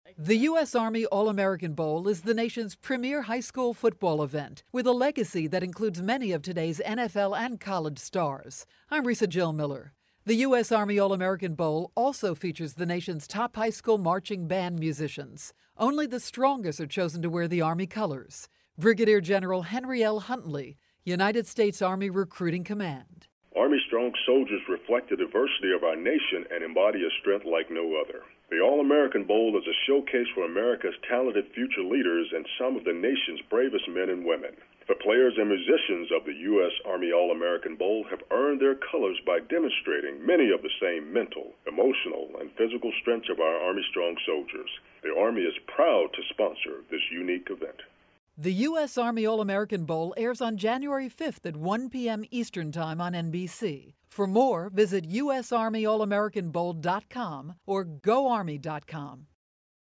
December 28, 2012Posted in: Audio News Release